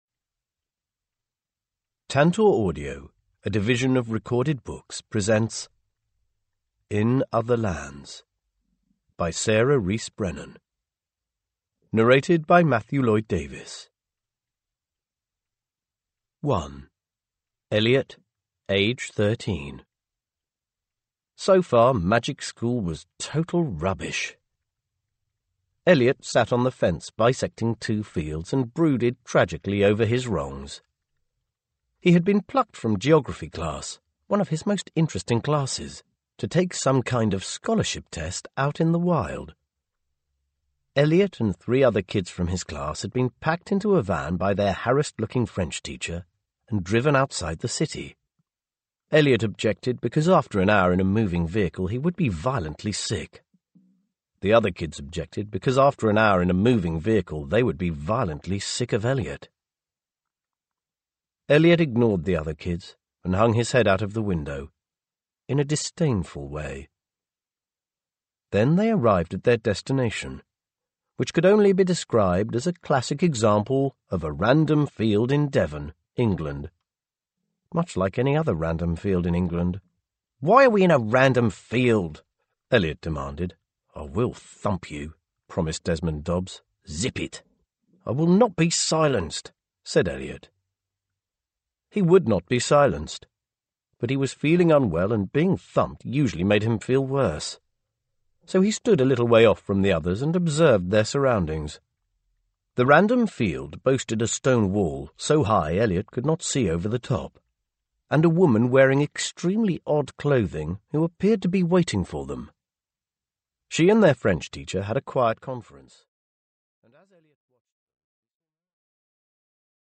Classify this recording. Genre: Audiobooks.